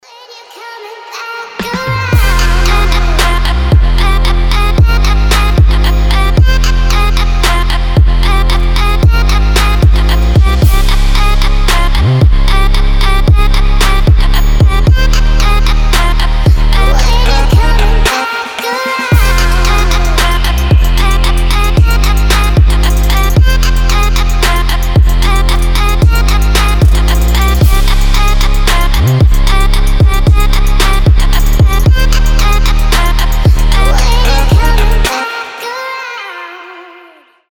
мощные басы
качающие
детский голос
Крутой трап с басами